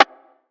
rim 10.wav